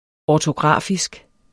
ortografisk adjektiv Bøjning -, -e Udtale [ ˌɒːtoˈgʁɑˀfisg ] Oprindelse jævnfør ortografi Betydninger vedr.